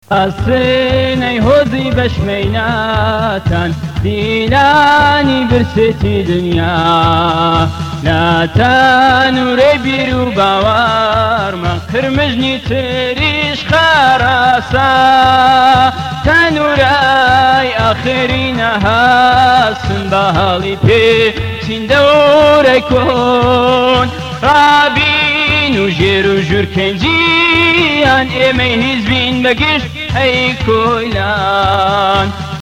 Here is a recording of part of a song in a mystery language.